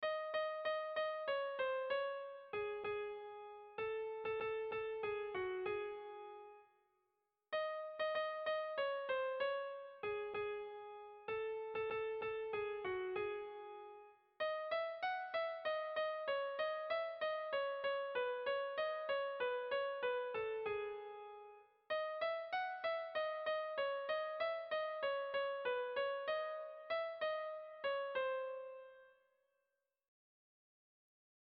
Tragikoa
Zortziko ertaina (hg) / Lau puntuko ertaina (ip)